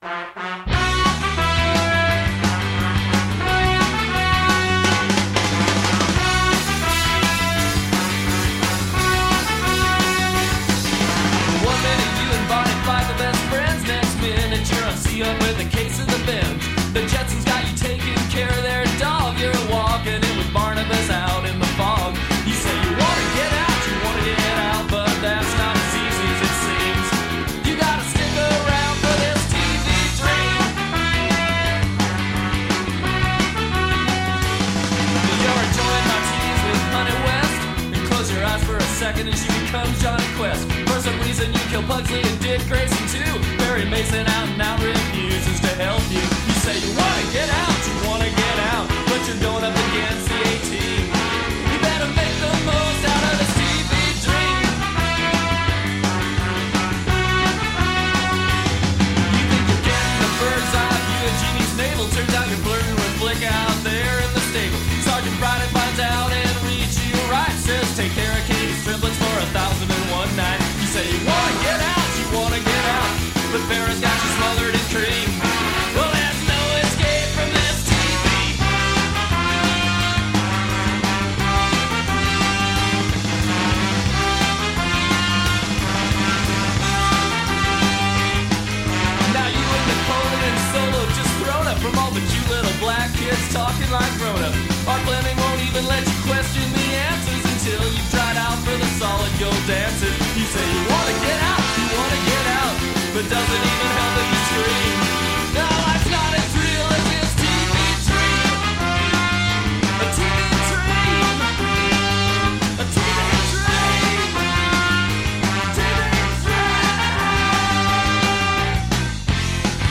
Radio Theatre: TV Dream (Audio)